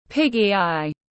Mắt ti hí tiếng anh gọi là piggy eye, phiên âm tiếng anh đọc là /ˈpɪɡ.i aɪ/ .